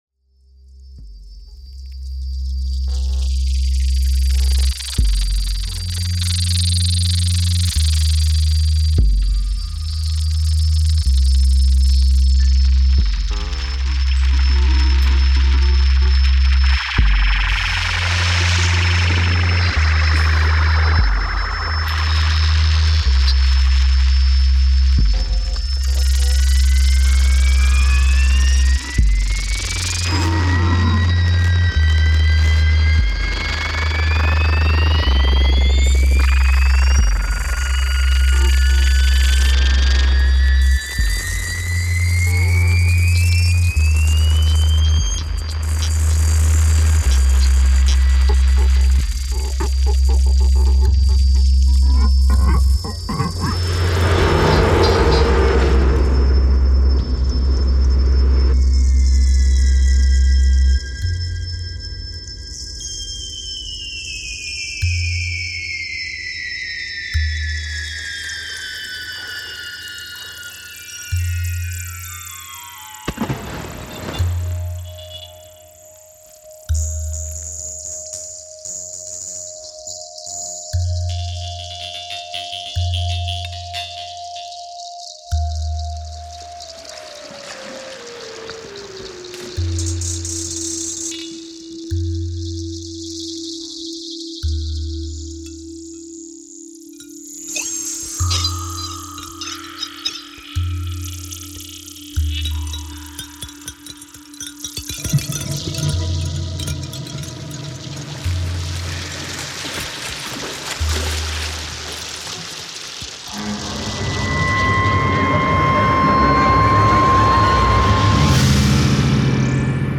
48 omnidirectional loudspeakers
The resulting piece OCEANIC presents us with an immersive four-dimensional environment evoking complex sound-metaphors around the sound motif of the oceanic wave movement, exploring the possibilities of the 4DSOUND spatial sound system, that allows the production of sound holograms.
Excerpt recorded in 4DSOUND system, after ambisonics and stereo conversion